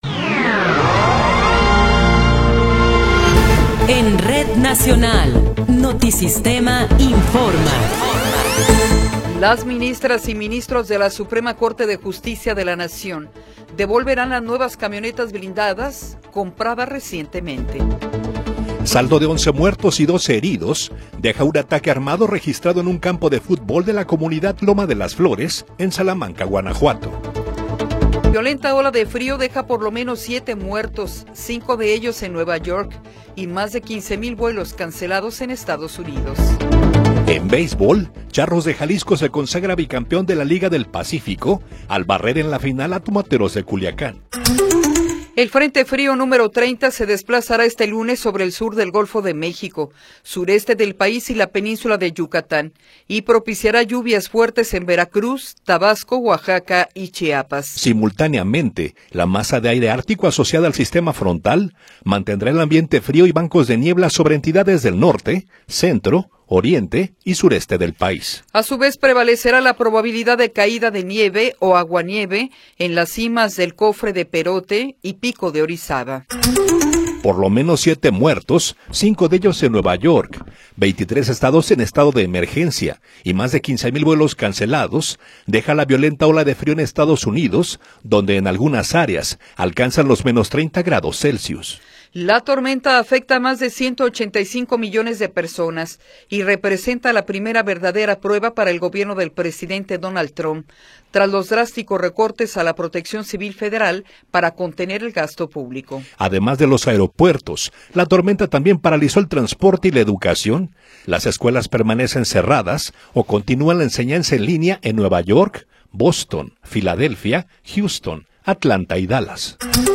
Noticiero 8 hrs. – 26 de Enero de 2026
Resumen informativo Notisistema, la mejor y más completa información cada hora en la hora.